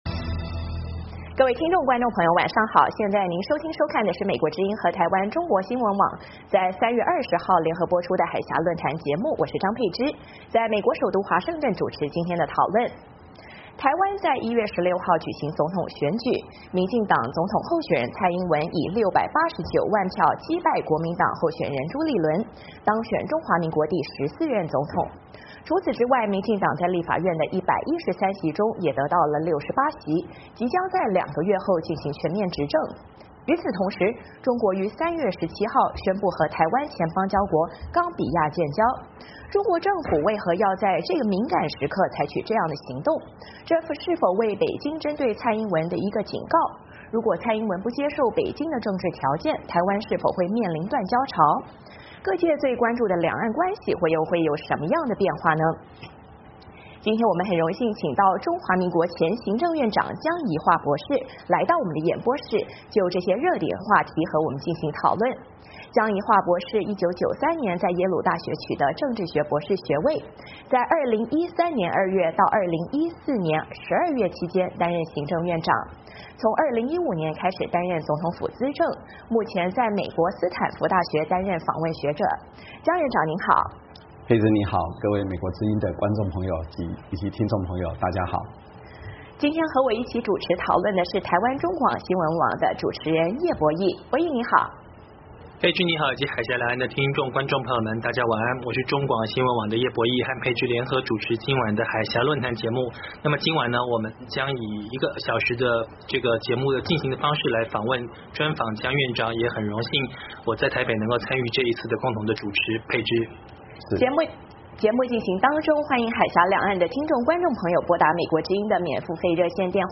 海峡论谈: 专访台湾前行政院长江宜桦谈蔡英文上任后两岸外交